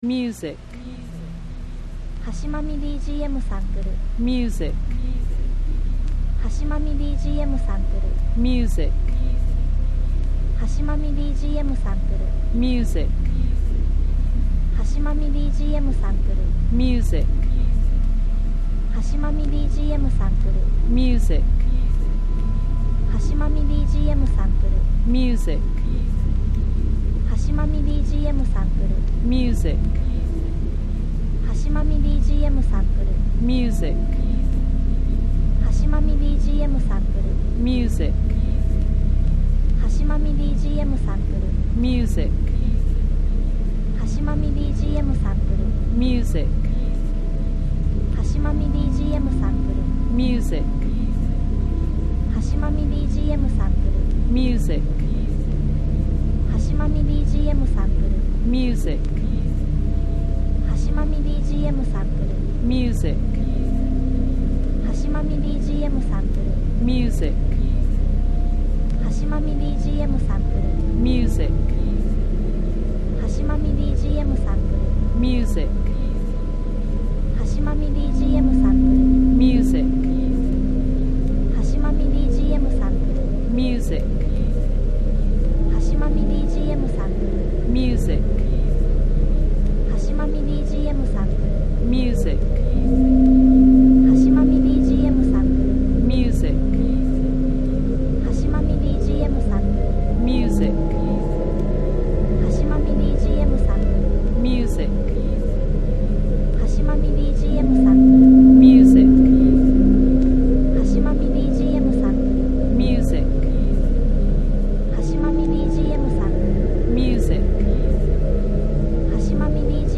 静かで淡々としているので、考察・異変・探索シーンにも合うBGM素材です。